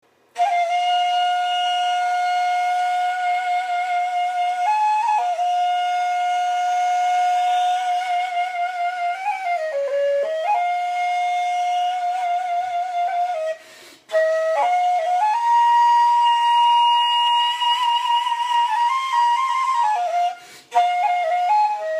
Красивая мелодия на курае